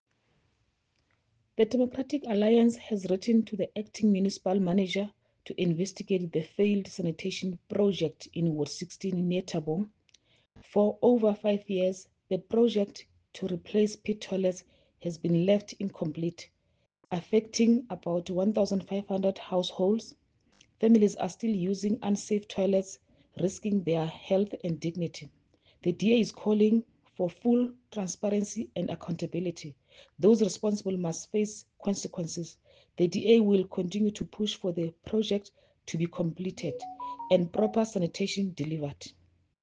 English soundbite by Cllr Luttiga Presente, Afrikaans soundbite by Cllr Jessica Nel and